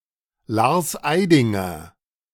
Lars Eidinger (German: [laʁs ˈaɪ̯dɪŋɐ]